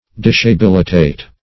Dishabilitate \Dis`ha*bil"i*tate\